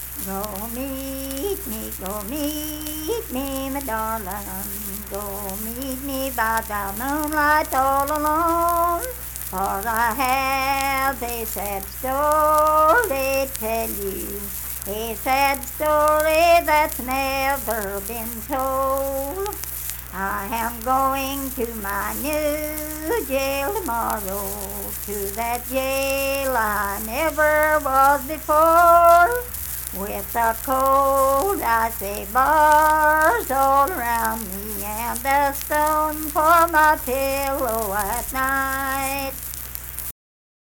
Unaccompanied vocal music performance
Verse-refrain 2(4).
Voice (sung)